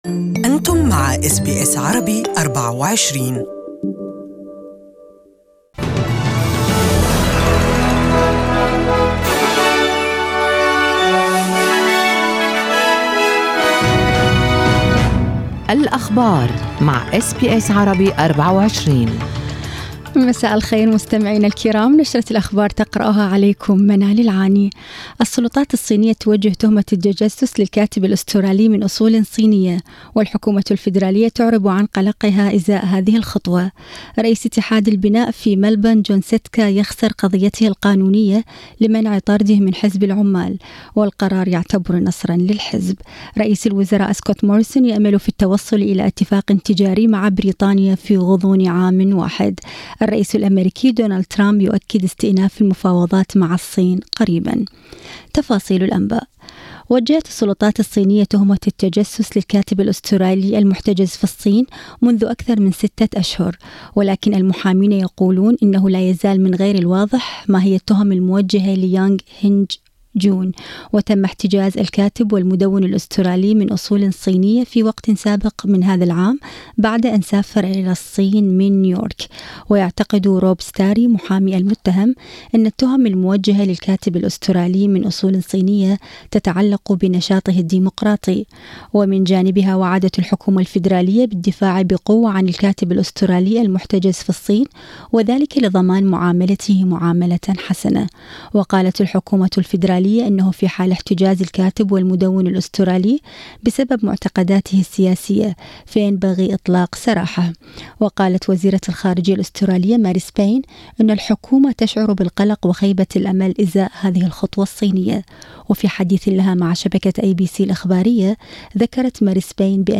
Evening News: Australia sees dramatic increase in accidental drug overdose deaths